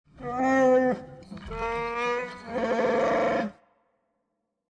Звуки Чубакки
Чубакка грозно рычит